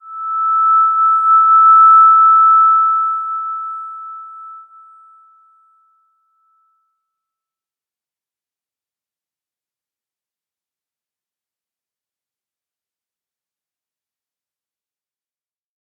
Slow-Distant-Chime-E6-p.wav